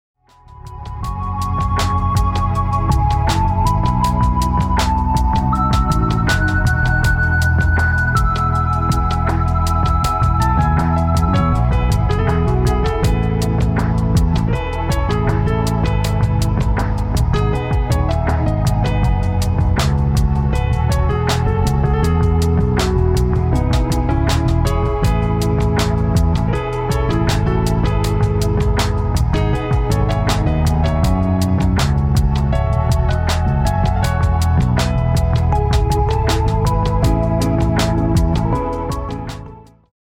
ジャンル：AIRアレンジサウンドアルバム